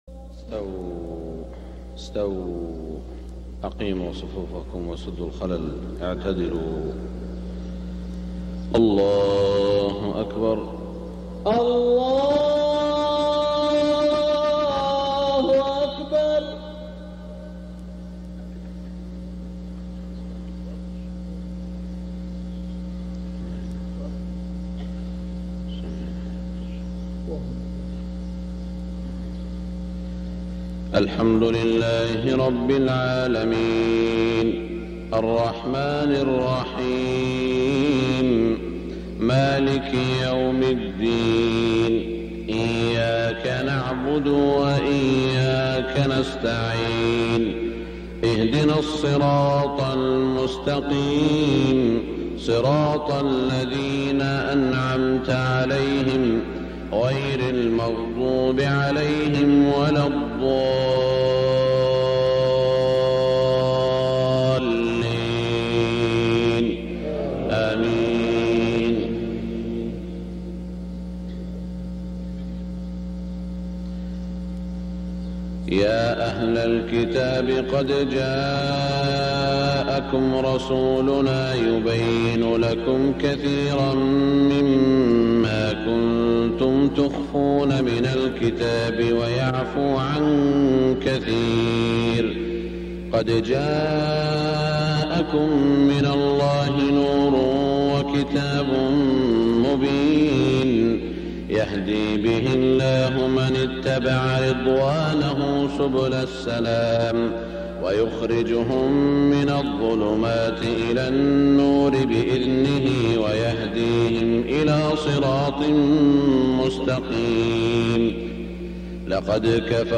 صلاة الفجر من المسجد الحرام 1421هـ من سورة المائدة 15-34 > 1421 🕋 > الفروض - تلاوات الحرمين